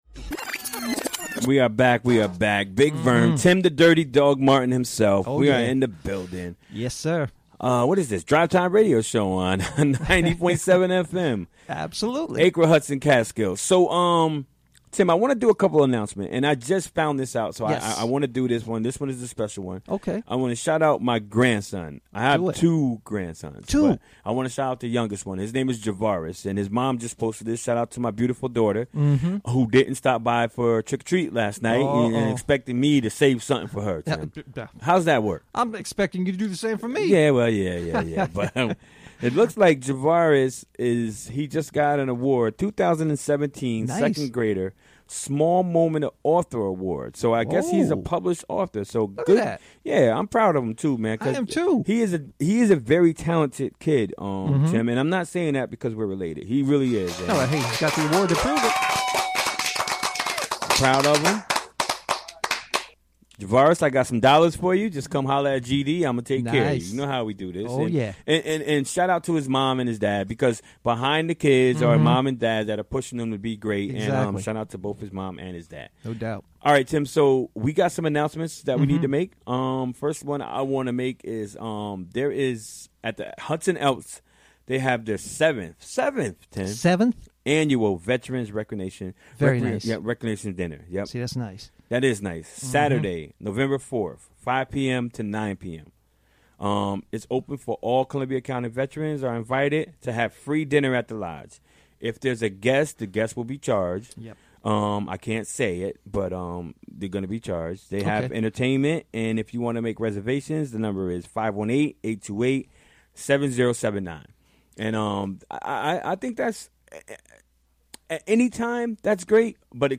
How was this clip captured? Recorded during the WGXC Afternoon Show Wednesday, November 1, 2017.